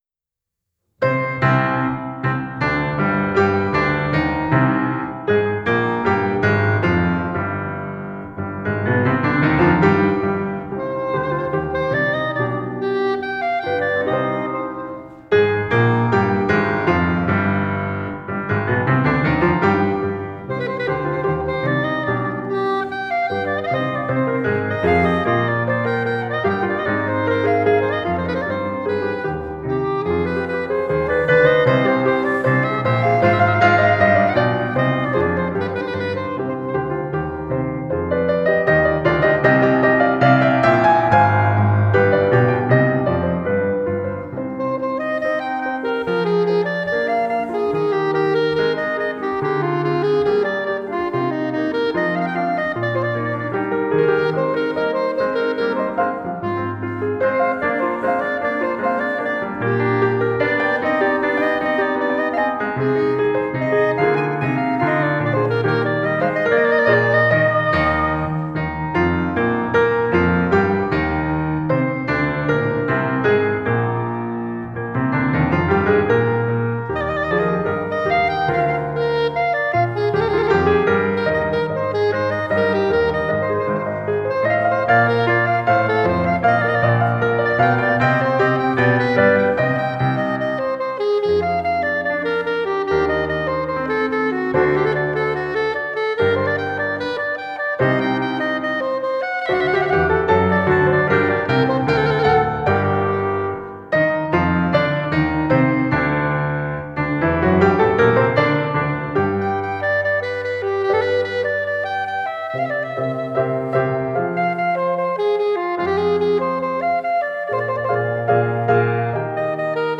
dropped in pitch from its original D minor to C minor
soprano saxophone
piano